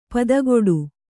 ♪ padagoḍu